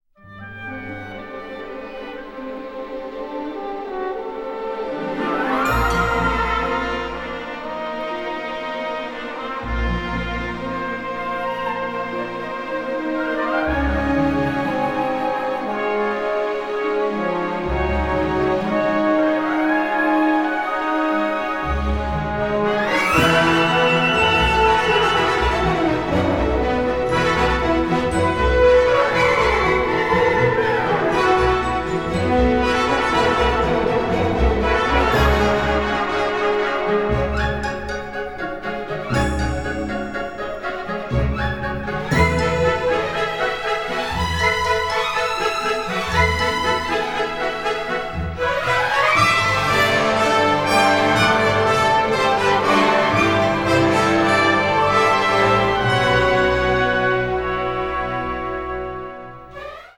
exciting, classic symphonic adventure score